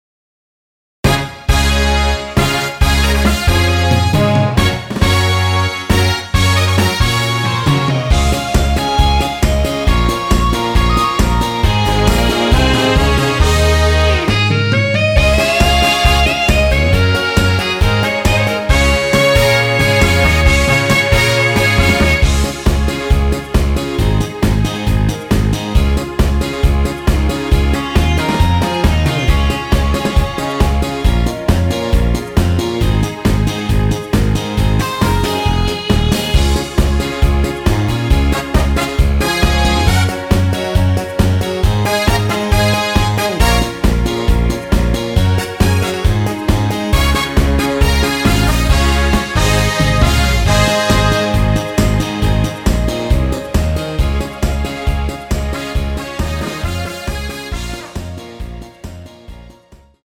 원키에서(+3)올린 멜로디 포함된 MR입니다.
Db
앞부분30초, 뒷부분30초씩 편집해서 올려 드리고 있습니다.
중간에 음이 끈어지고 다시 나오는 이유는